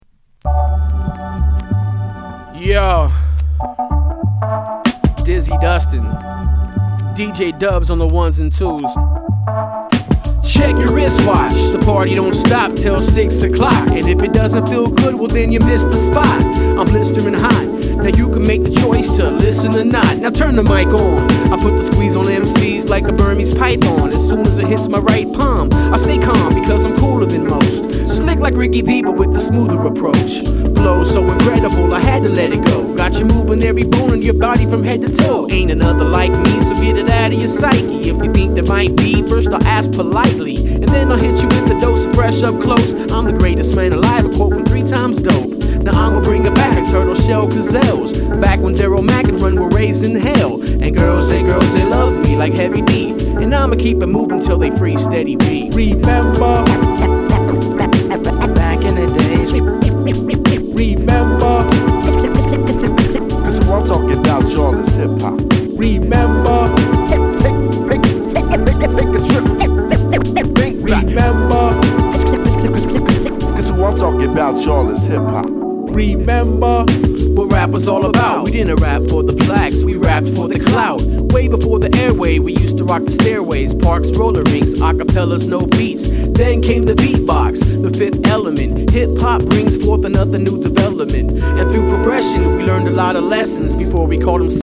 [12”]ブレイクビーツ